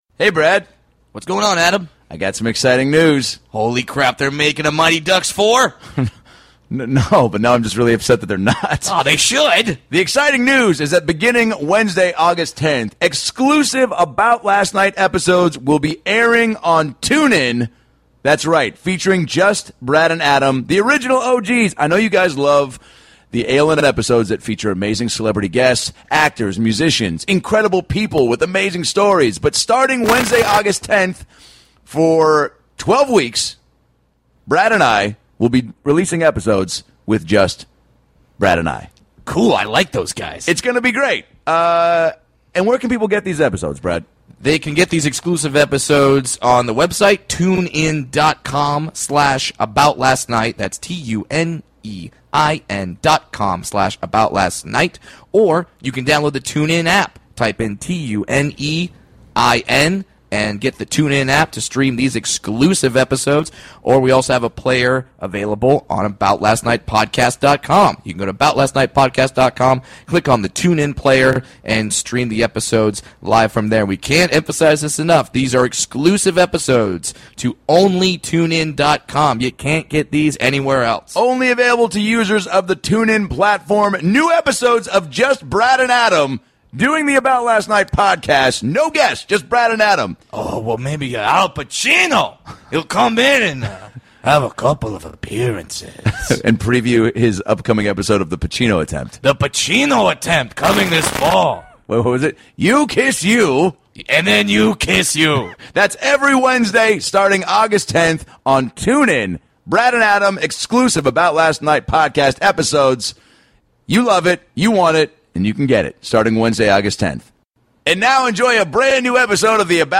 Jim Jefferies is hands down one of the best comics in the word, and this conversation proves he's also one of the funniest off stage as well. From meeting Al Pacino, to his early roots in Australia, to bar fights in England, it's laughs from start to finish.